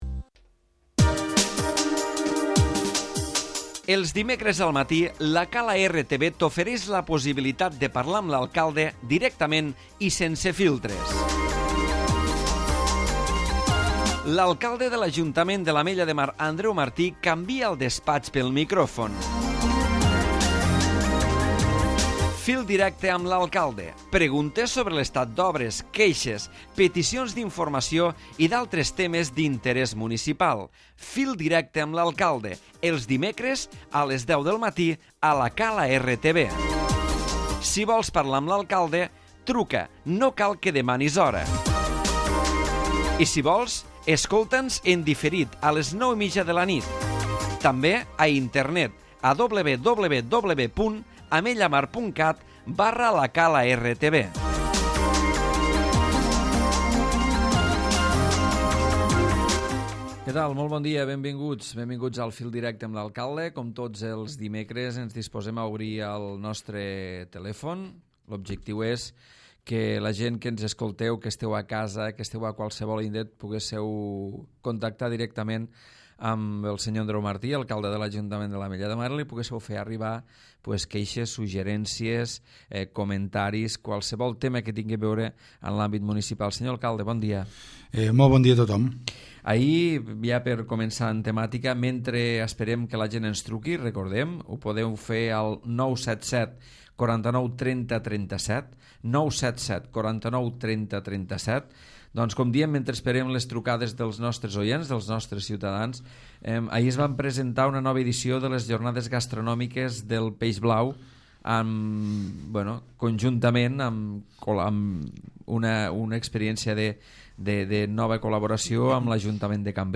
Andreu Martí, alcalde de l'Ajuntament de l'Ametlla de Mar se sotmet a les trucades dels oients i repassa el dia a dia de l'Ajuntament de l'Ametlla de Mar.